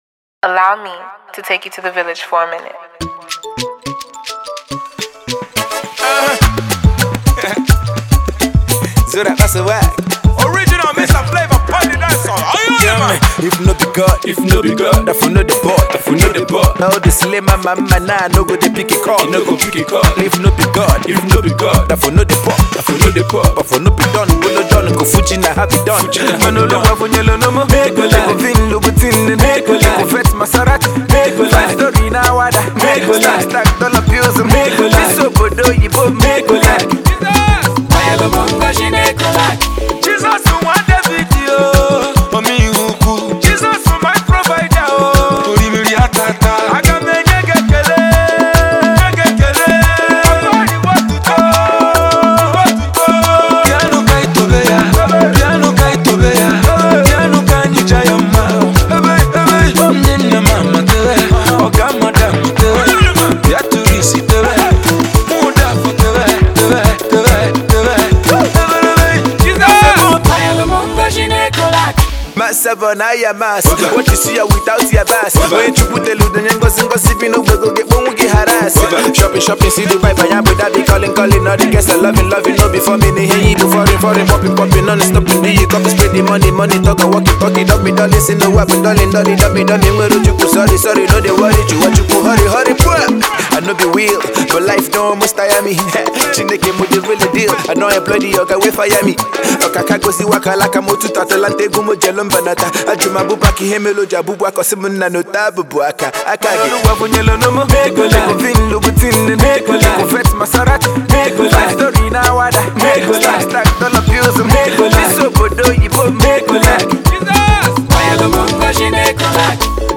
Indigenous rapper